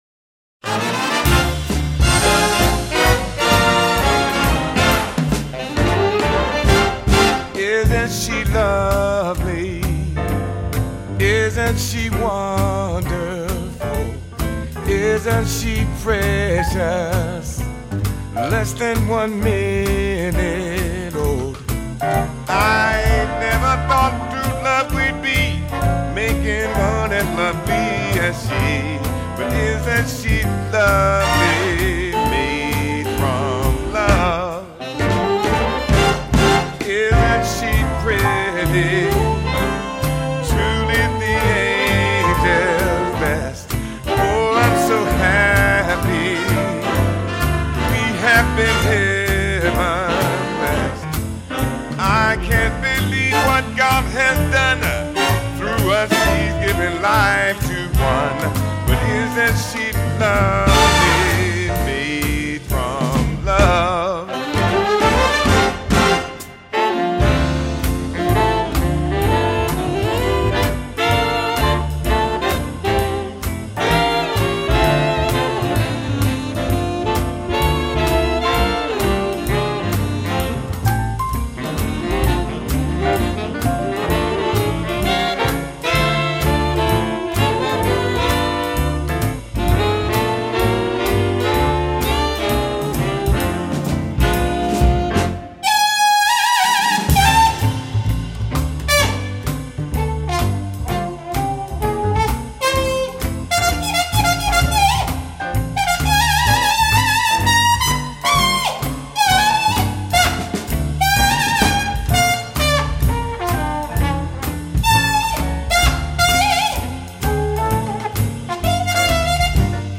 baritone saxophone